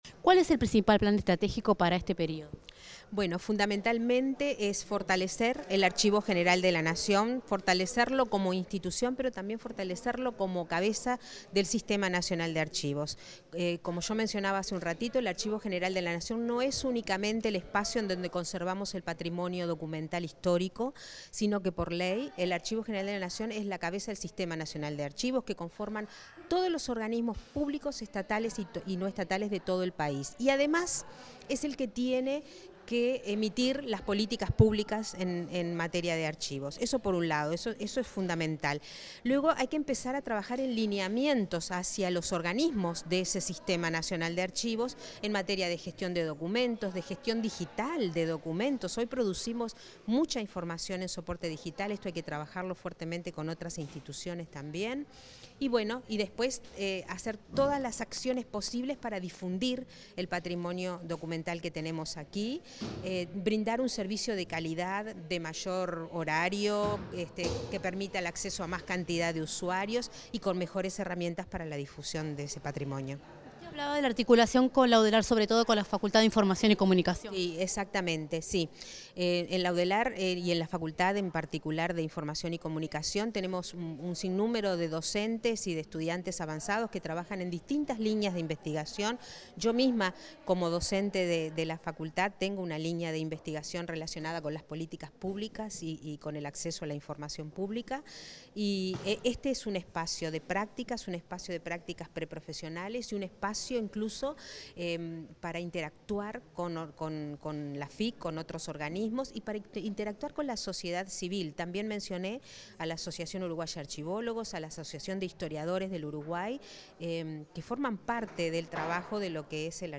Declaraciones de la directora del Archivo General de la Nación, Alejandra Villar
La nueva directora del Archivo General de la Nación, Alejandra Villar, dialogó con la prensa luego de asumir su cargo.